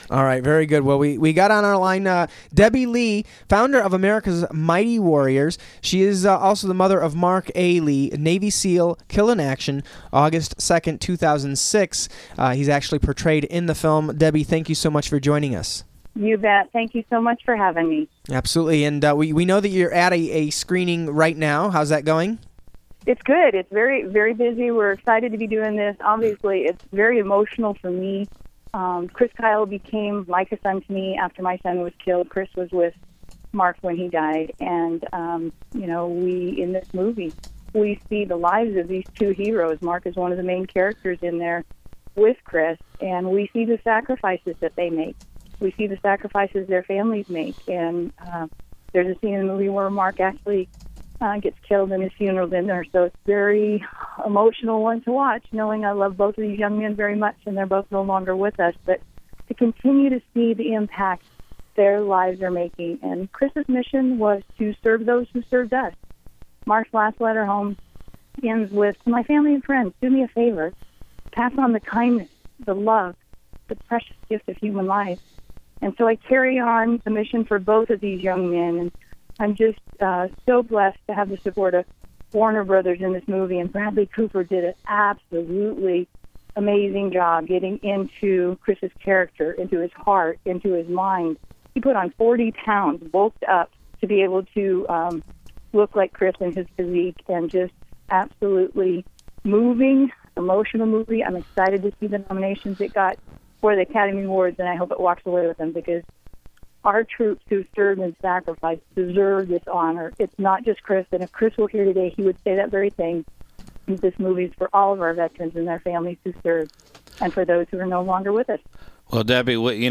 Breakthrough Interviews